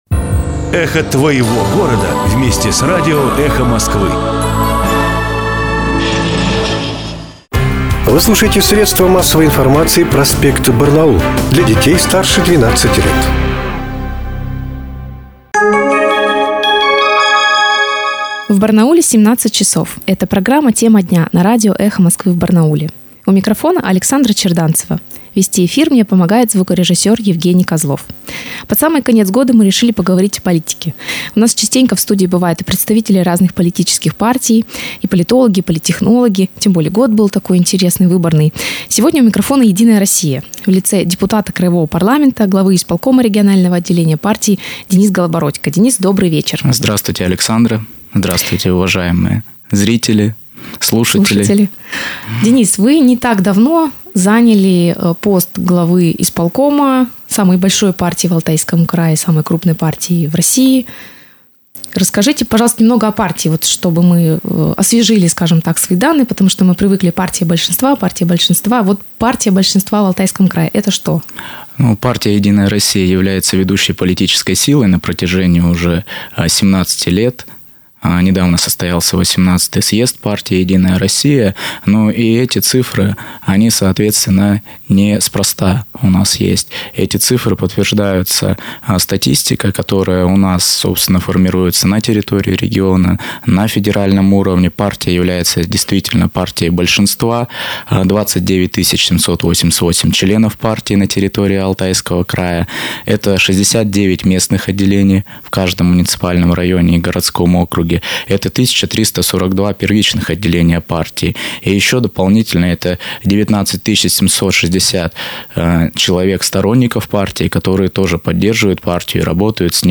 Кроме того, около 20% членов партии в регионе скоро могут потерять свои партбилеты по итогам прошедшего аудита. Об этом в эфире радио Эхо Москвы в Барнауле рассказал депутат краевого парламента, глава исполкома регионального отделения партии Денис Голобородько.